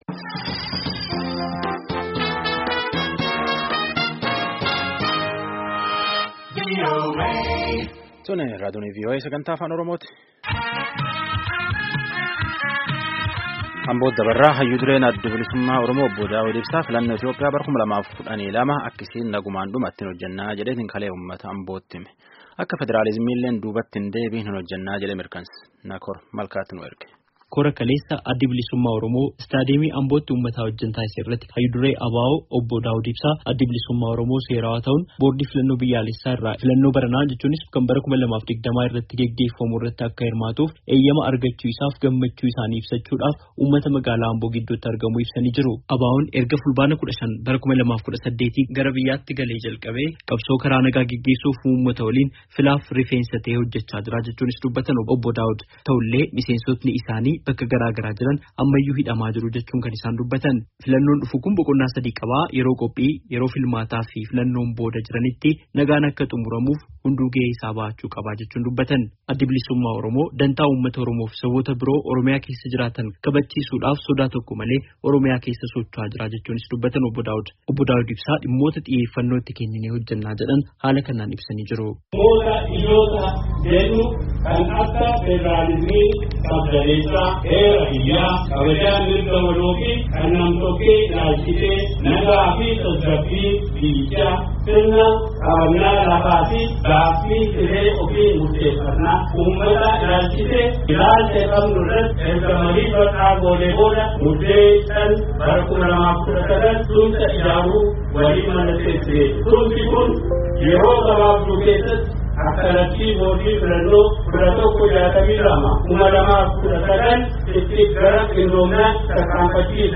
Hayyu-dureen ABO, Obbo Daawud Ibsaa, kora kana irratti argamuun haasawa dhageessisaniin filannoon Biyyaalessaa barana geggeeffamu nagaan akka xumuramuuf hundinuu gahee irraa eegamu akka bahatu waamicha dhiheessan.
Gabaasaa guutuu caqasaa.